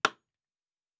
Nail 5_2.wav